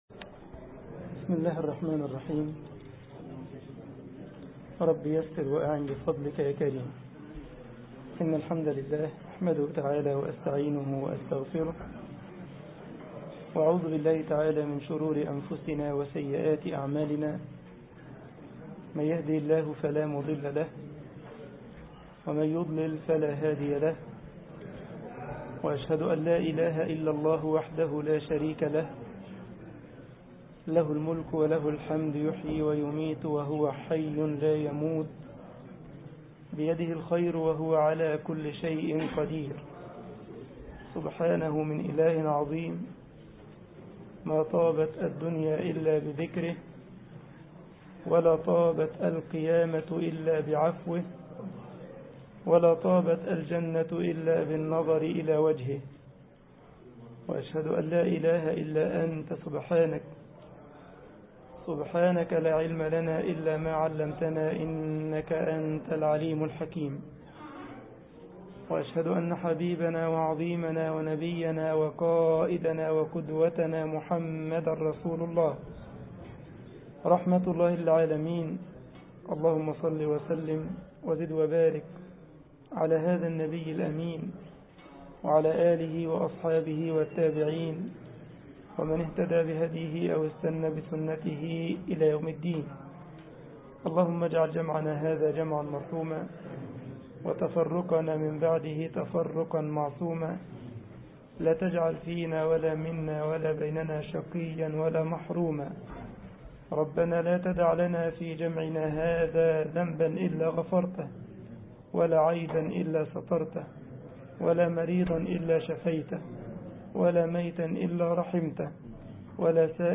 مسجد الجمعية الإسلامية بالسارلند ـ ألمانيا درس